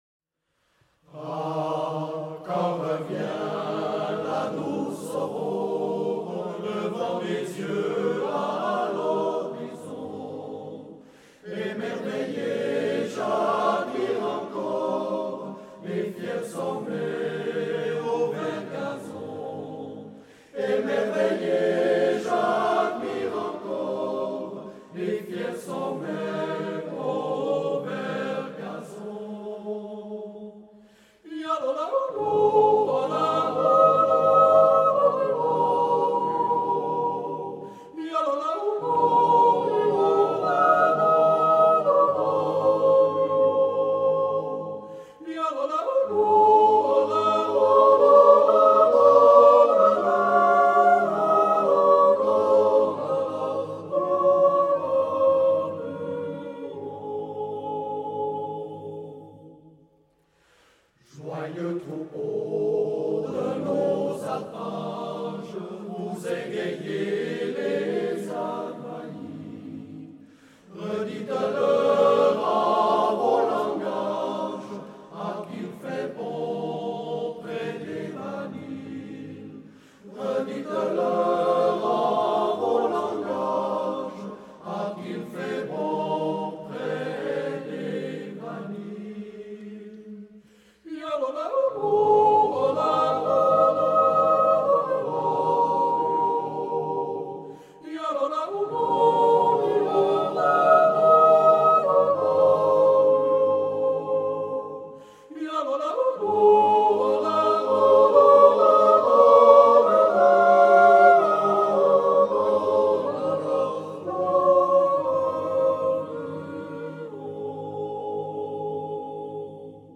A Swiss National Yodeling Festival